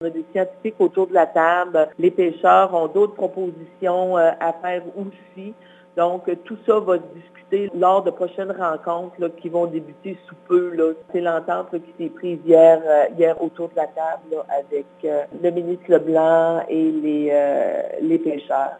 Pour sa part, la députée fédérale de la région, Diane Lebouthillier, a martelé en point de presse aujourd’hui que son gouvernement accompagnera les travailleurs touchés, en proposant notamment une reprise des jours de pêche perdus à l’automne :